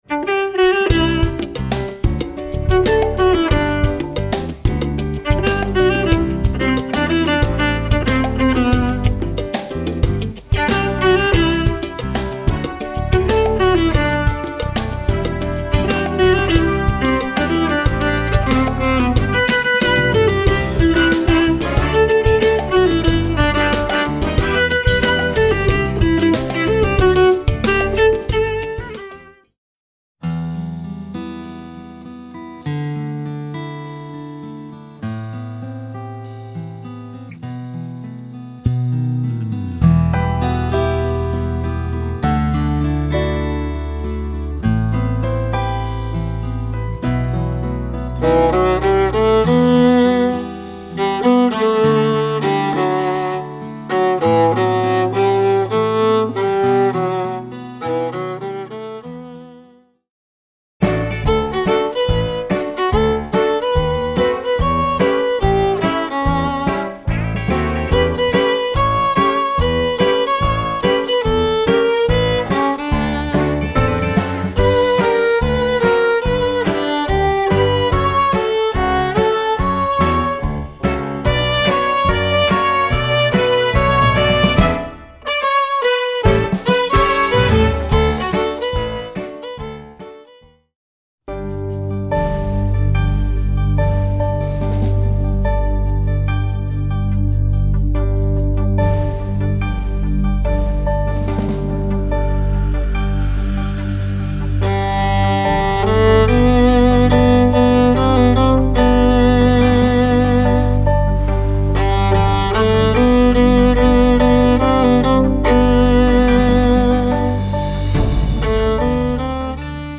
Voicing: Viola w/ Audio